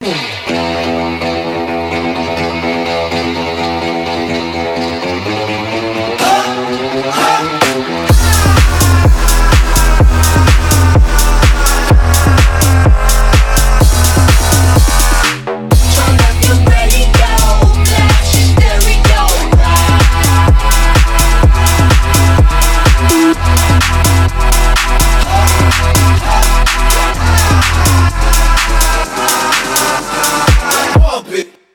Архив Рингтонов, Клубные рингтоны